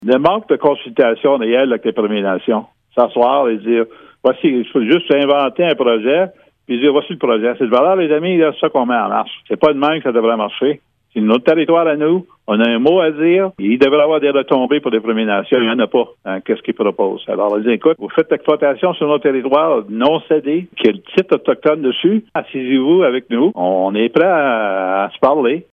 Le chef de bande rappelle que tant que les entreprises forestières et les gouvernements sont en mesure d’imposer des projets aux communautés autochtones, il ne sera pas possible d’aller de l’avant :